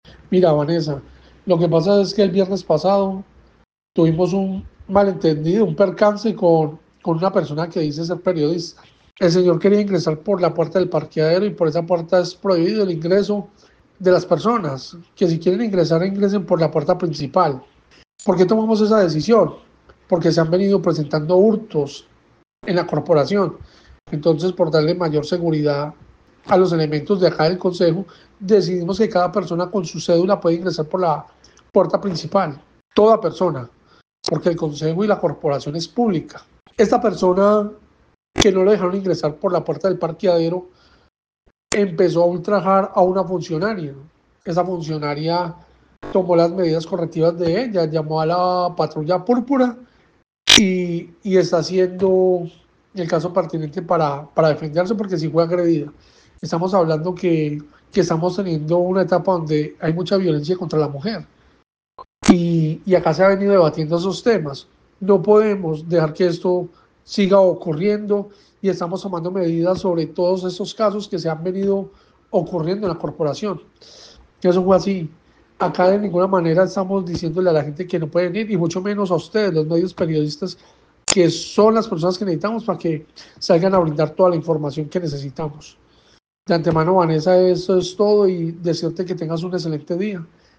Presidente del Concejo de Armenia